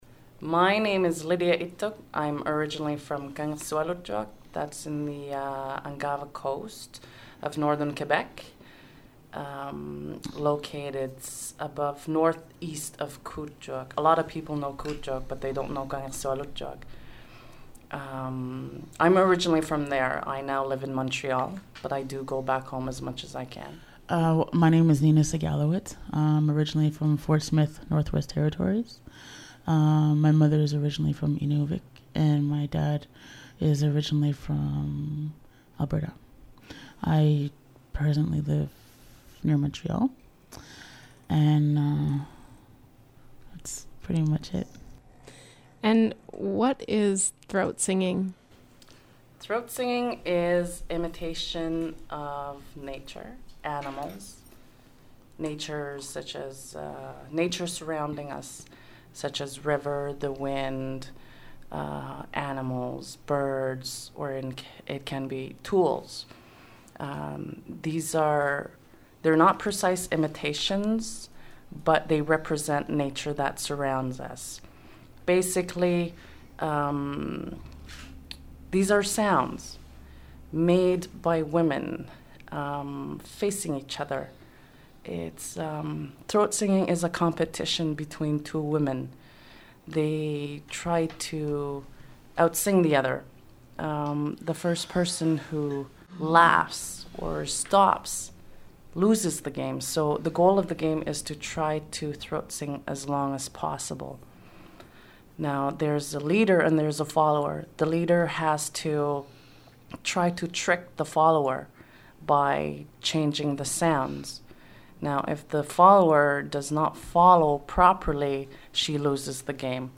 Inuit throat singers continue ancient tradition